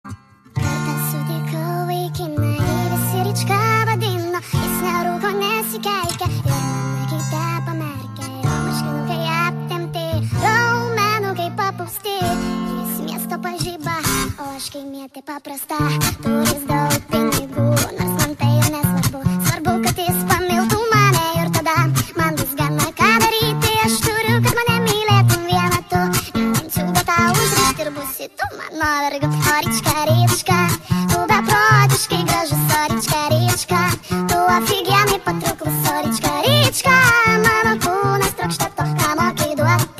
детский голос , поп